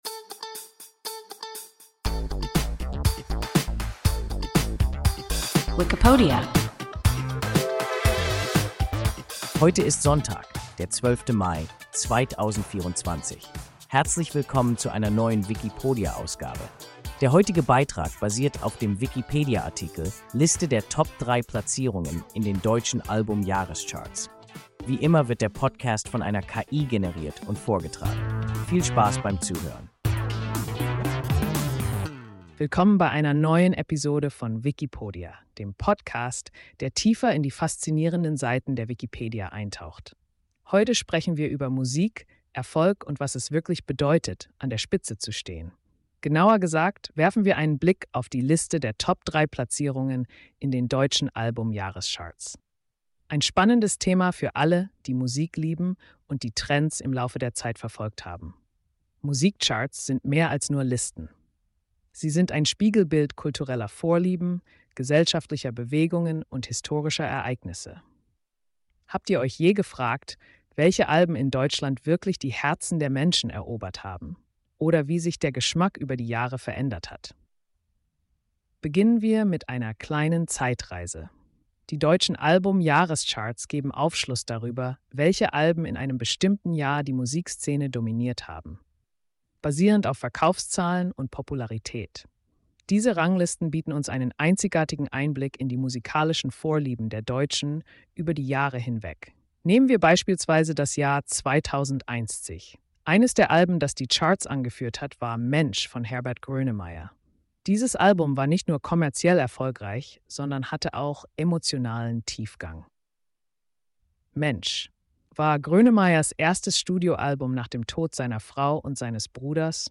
Liste der Top-3-Platzierungen in den deutschen Album-Jahrescharts – WIKIPODIA – ein KI Podcast